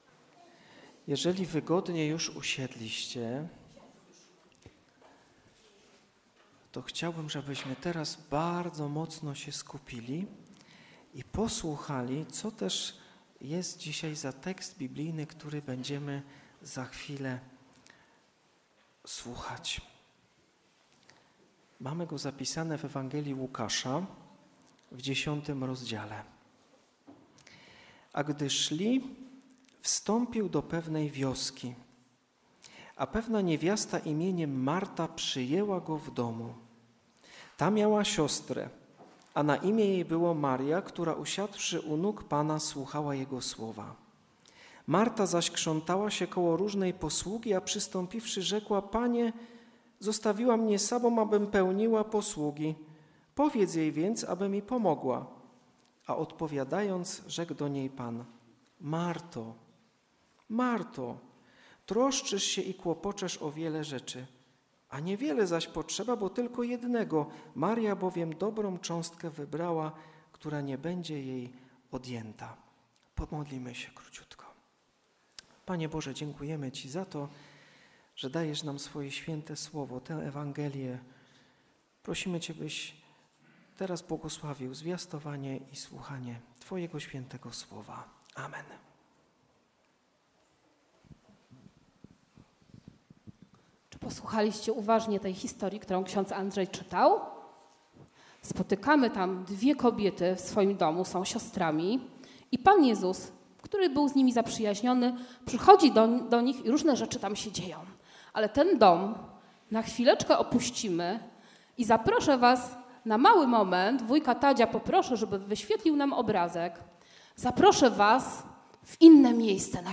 Nabożeństwo rodzinne – Łk 10,38-42
Święto: 3. Niedziela Adwentu – Nabożeństwo rodzinne